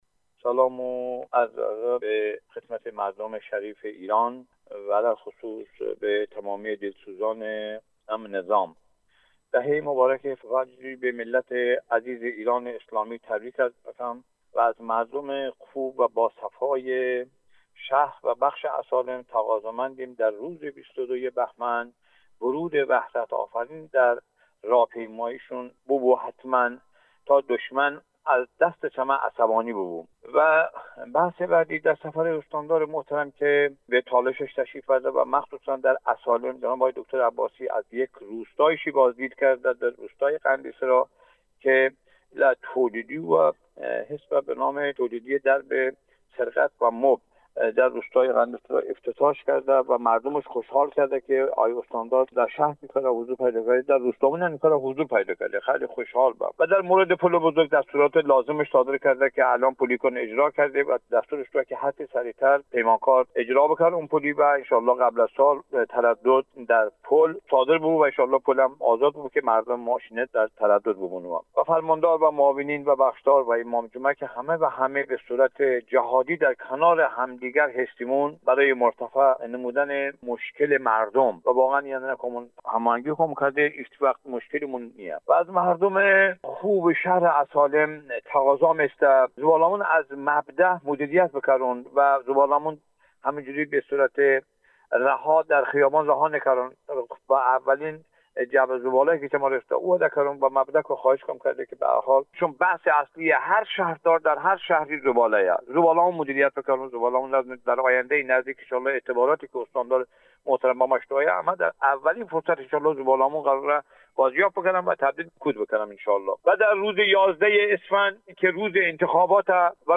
De islamiyə inğilobi səbarz bıə 10 rujon mınosibəti cənab Sedağətdust(Əsalemı şəhrdar) sıxanon